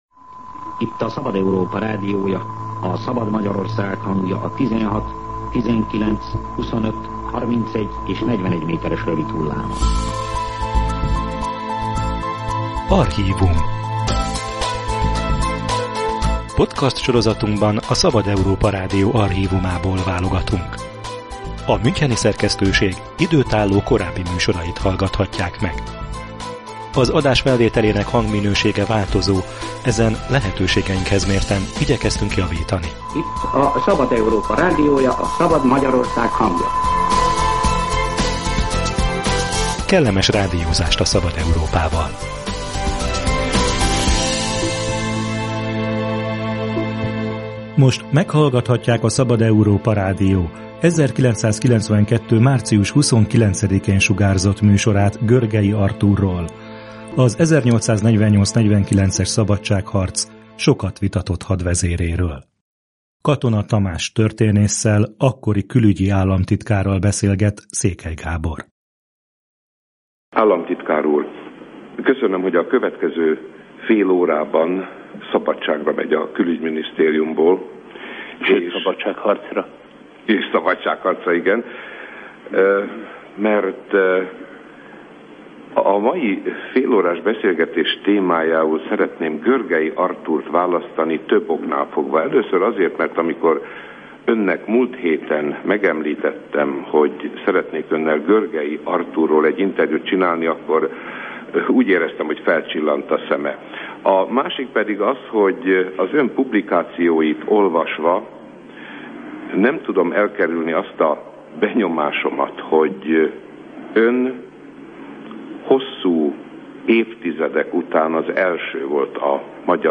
1992-ben a Szabad Európa Rádió kedvéért egy fél órára félretette külügyi államtitkári pozícióját és eredeti szakmájában, történészként nyilatkozott Katona Tamás az 1848-49-es szabadságharc vitatott alakjáról, Görgey Artúrról. Hogyan lett Kossuth követőjéből áruló és bűnbak — erről szól a műsor.